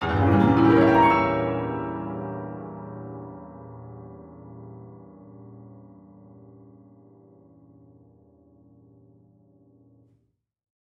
Index of /musicradar/gangster-sting-samples/Chord Hits/Piano
GS_PiChrdTrill-Edim.wav